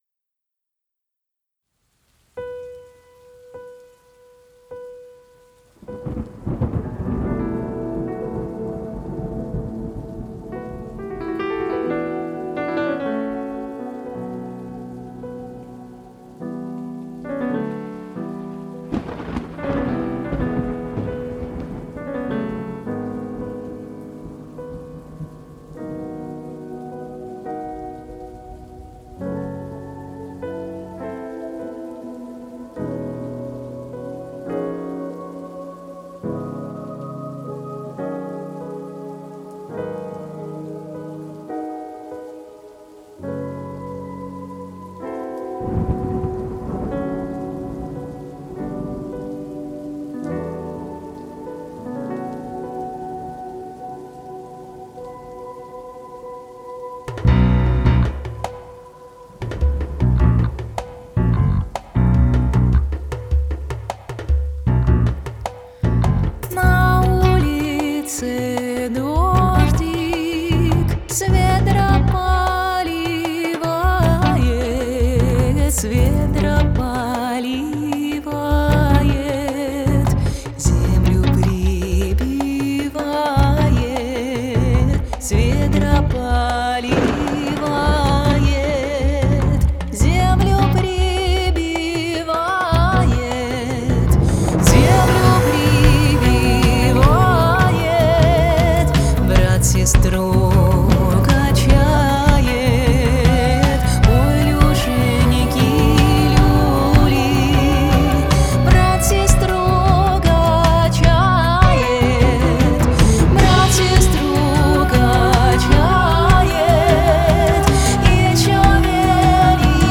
Все живье, барабаны бас писаны в питере в добролете) Вокал в Уфе) Гитары в Белгороде мною ))